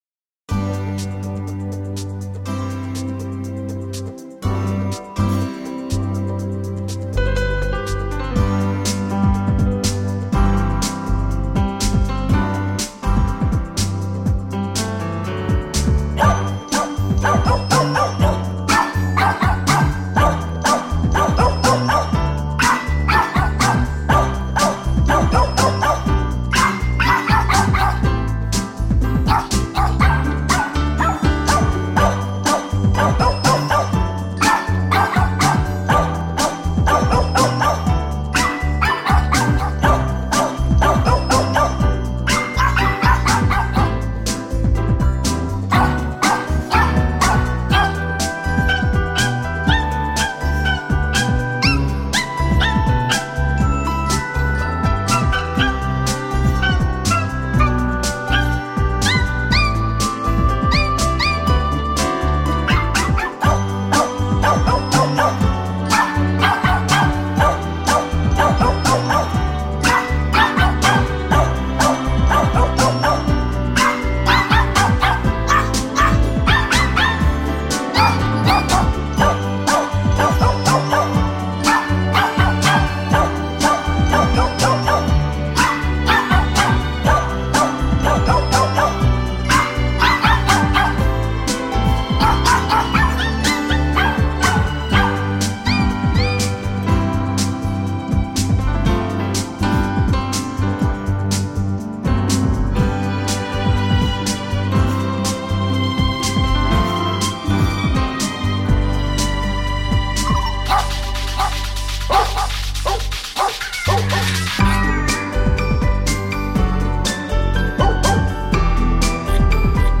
感受自然，体会优雅，婉转悠扬，柔美宁静...